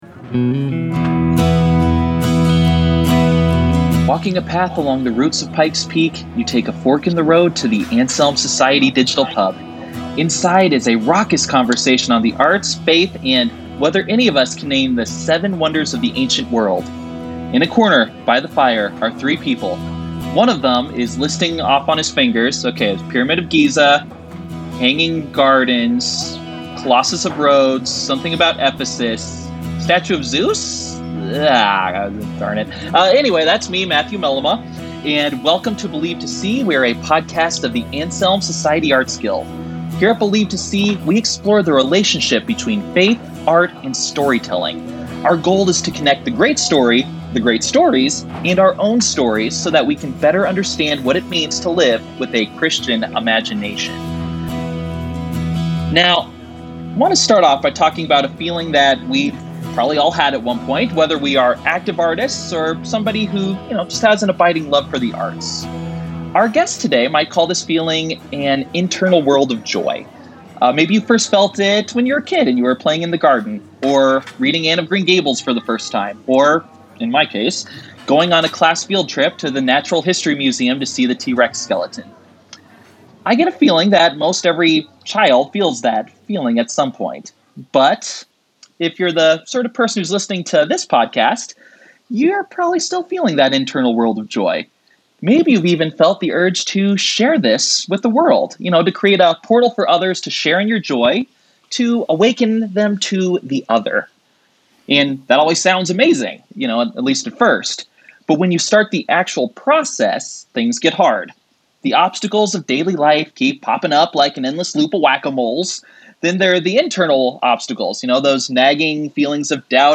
Podcast Interview
Recent podcast interview: